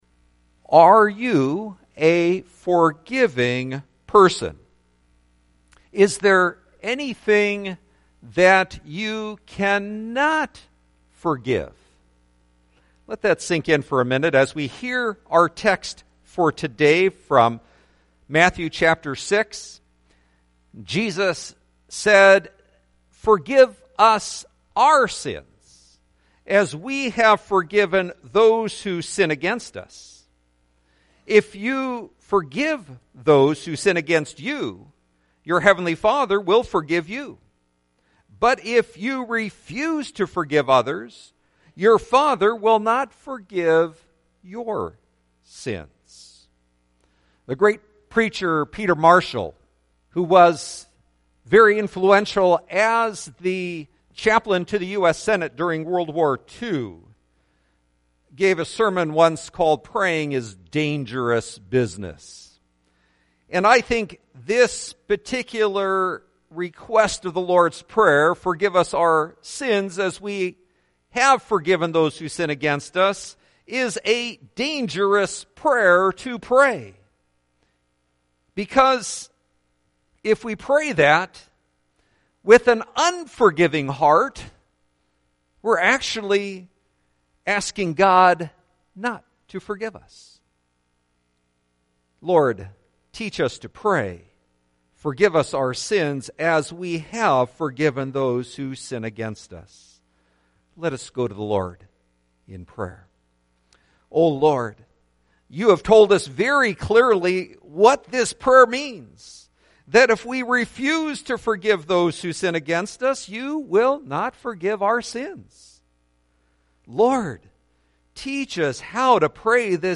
Lord teach us to pray Listen To Sermon